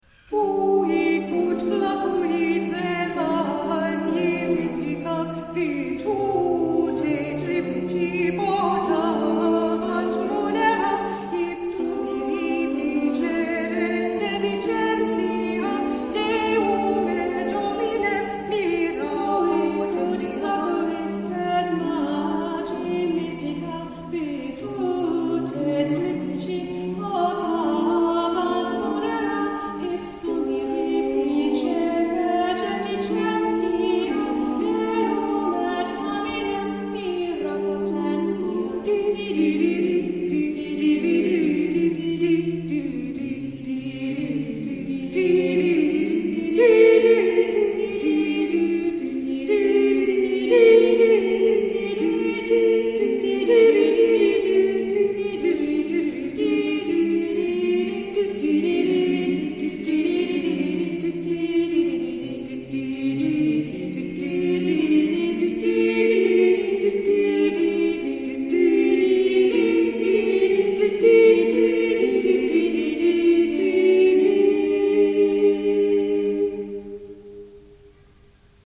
Huic ut placuit — (vocale e strumentale) | Mediaeval Magic | Lumina Vocal Ensemble | 2009